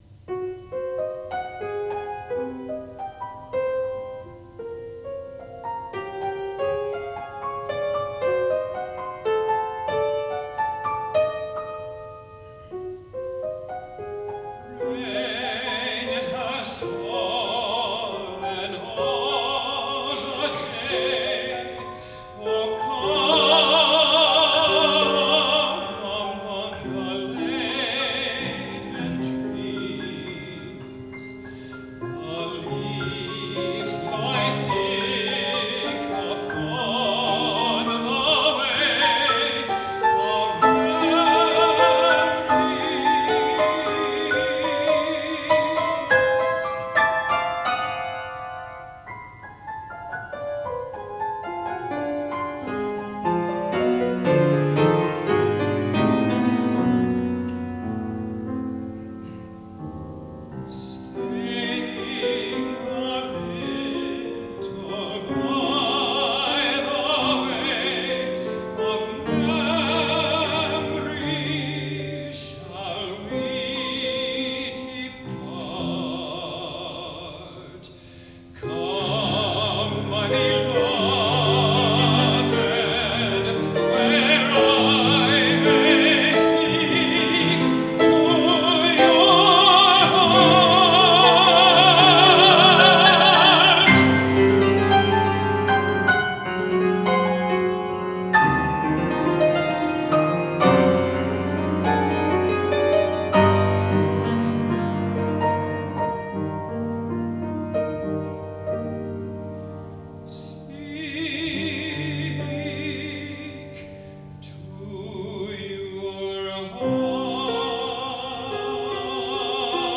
These three Barber Songs are among our favorites, performed in Kentucky in 2013